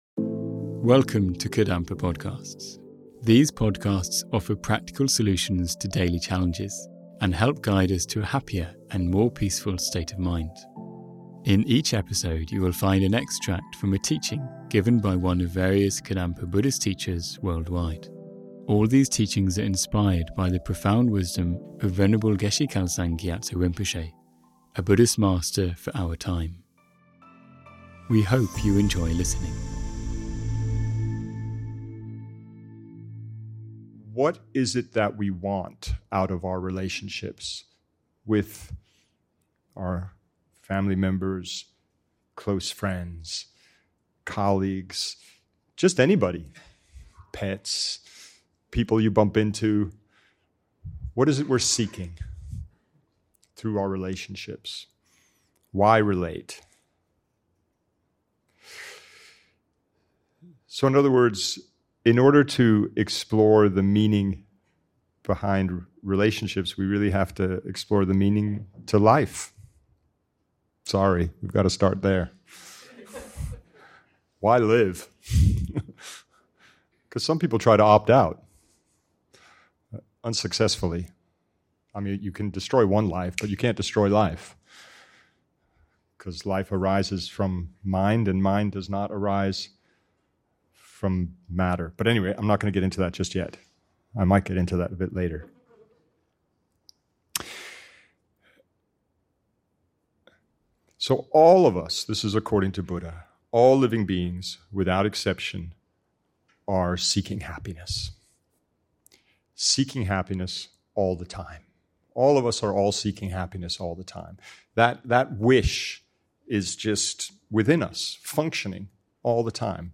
Why do we seek relationships, and how can we find fulfilment in them? This teaching extract explores our universal search for lasting happiness.